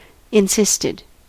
Ääntäminen
Ääntäminen US Haettu sana löytyi näillä lähdekielillä: englanti Käännöksiä ei löytynyt valitulle kohdekielelle. Insisted on sanan insist partisiipin perfekti.